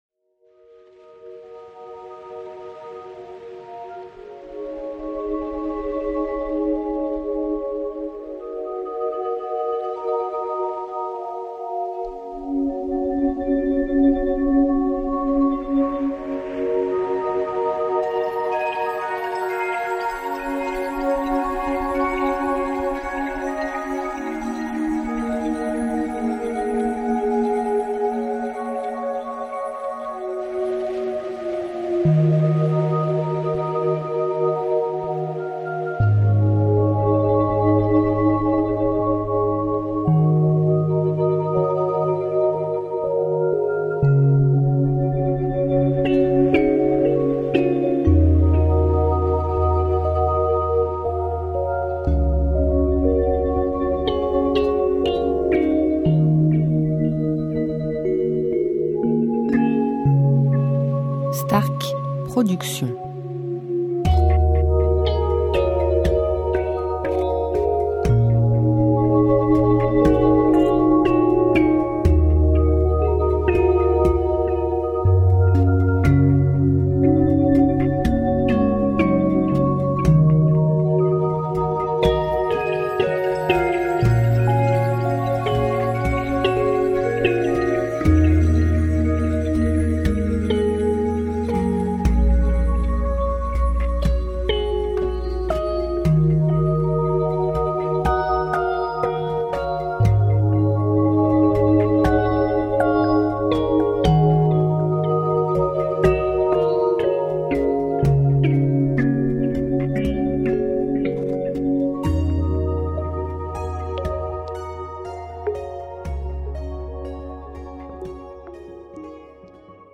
style Californien durée 1 heure